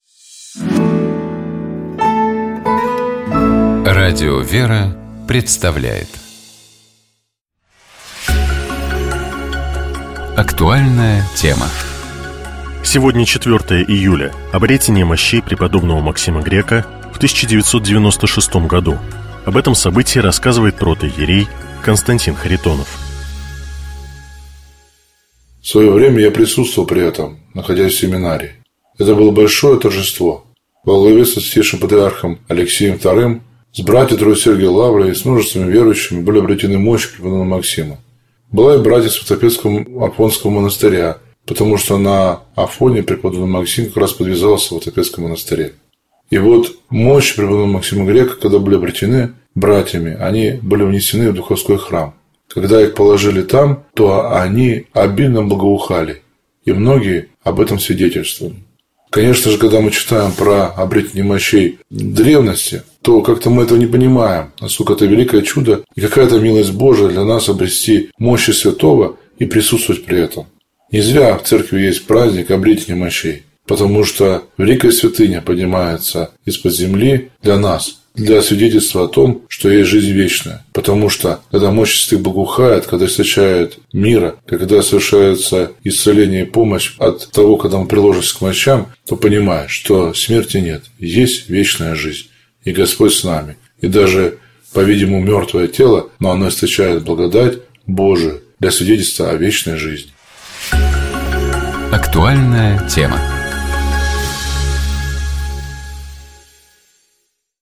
Об этом событии рассказывает протоиерей